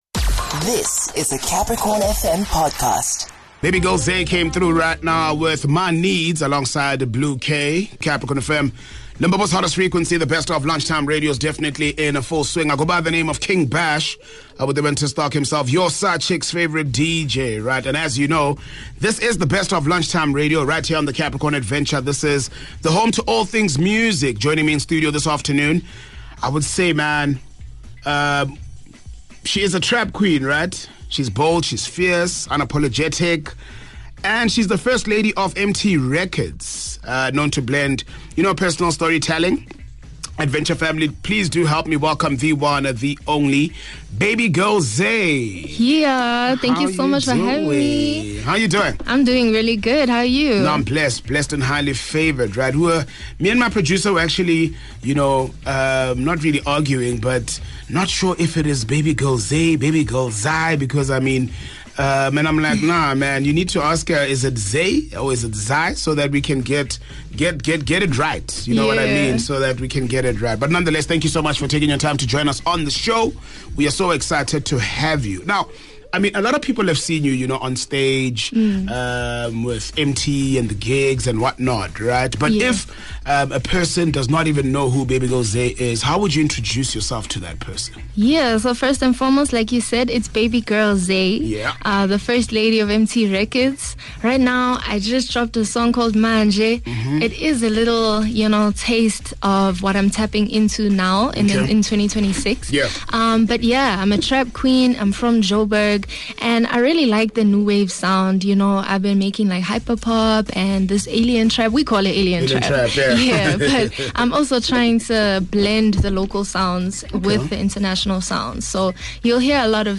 joined in studio